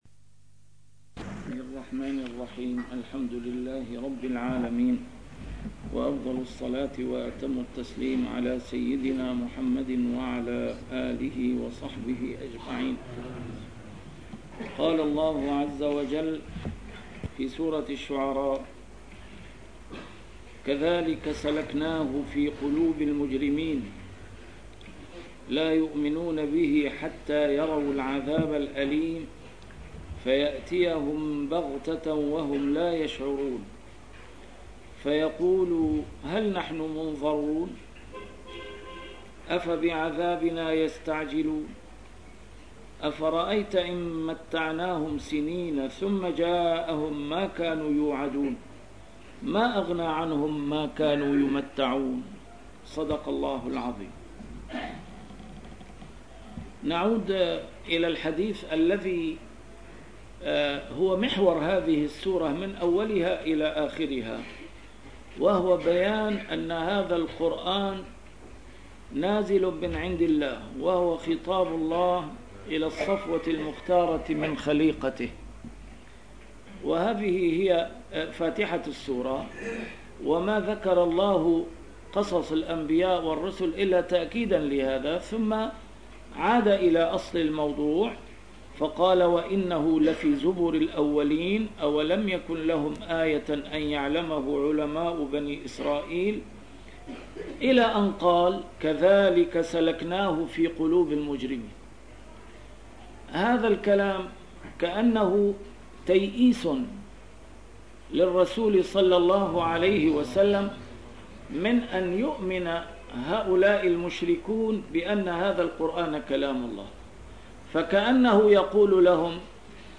A MARTYR SCHOLAR: IMAM MUHAMMAD SAEED RAMADAN AL-BOUTI - الدروس العلمية - تفسير القرآن الكريم - تسجيل قديم - الدرس 240: الشعراء 200-204
تفسير القرآن الكريم - تسجيل قديم - A MARTYR SCHOLAR: IMAM MUHAMMAD SAEED RAMADAN AL-BOUTI - الدروس العلمية - علوم القرآن الكريم - الدرس 240: الشعراء 200-204